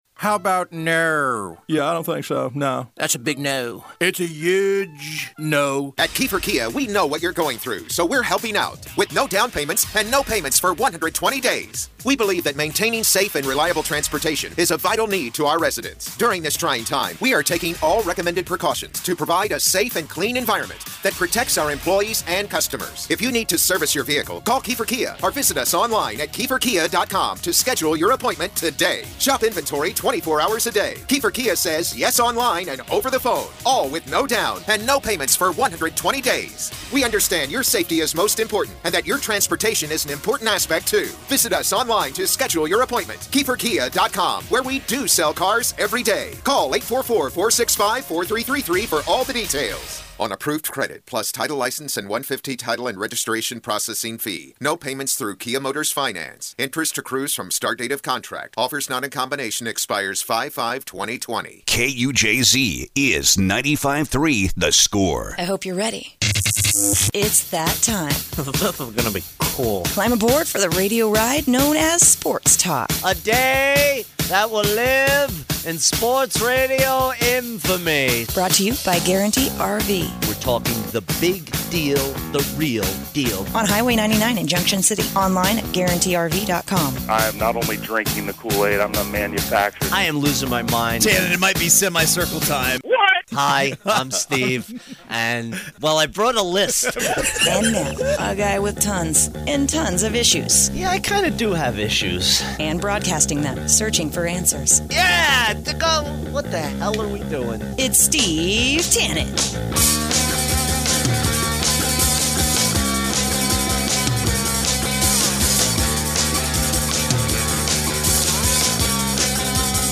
Flashback Interview